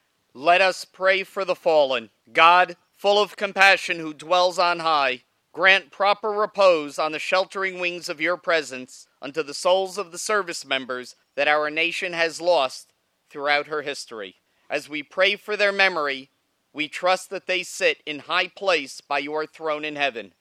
FORT RILEY – A Memorial Day ceremony honored fallen soldiers Monday at the Fort Riley post cemetery.
prayed to close the ceremony.